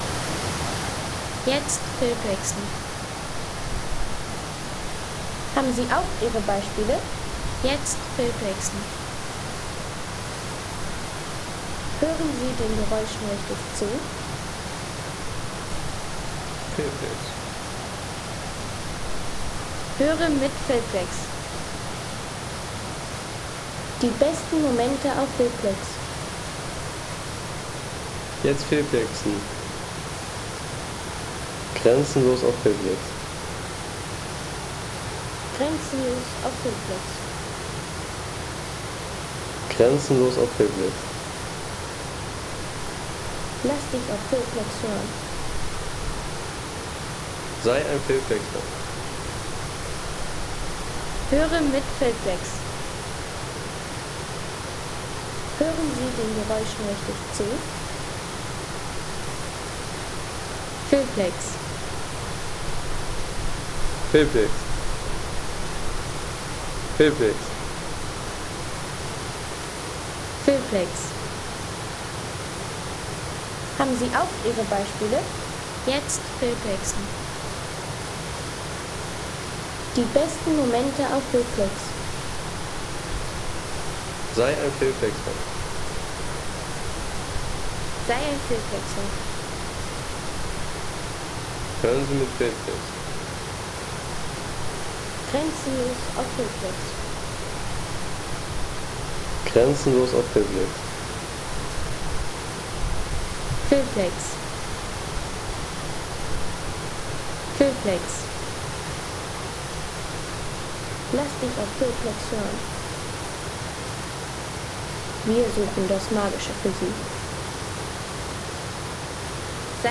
Toce-Wasserfall
Toce-Wasserfall Home Sounds Landschaft Wasserfälle Toce-Wasserfall Seien Sie der Erste, der dieses Produkt bewertet Artikelnummer: 34 Kategorien: Landschaft - Wasserfälle Toce-Wasserfall Lade Sound.... Der Toce-Wasserfall: Ein beeindruckendes Naturschauspiel.